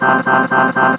rick_kill_vo_02.wav